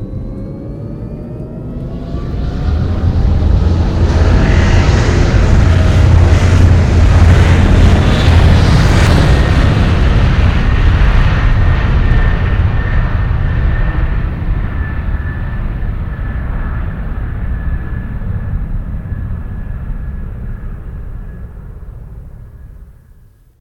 takeoff.ogg